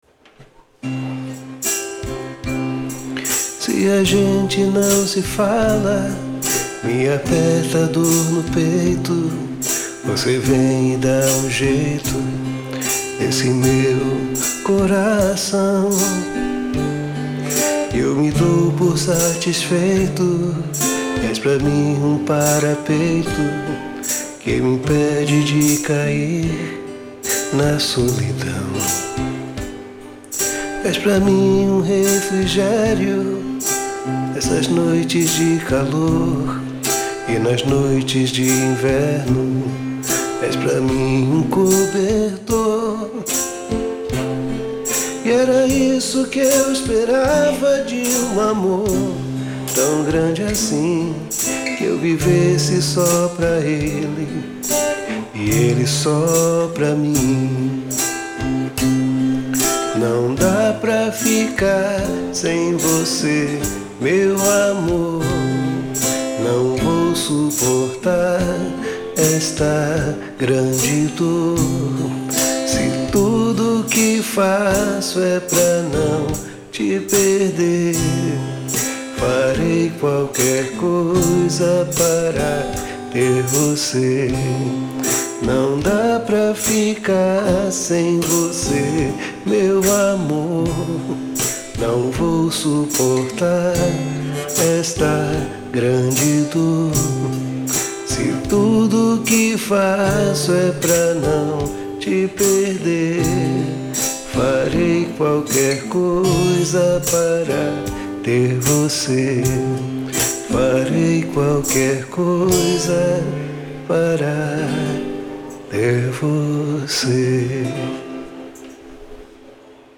Alguém tocando o violão.
Intérprete: Grupo Terra (Ensaio)
Gravada durante os ensaios do Grupo Terra, de Iaçu.
Parapeito__acustica_2.mp3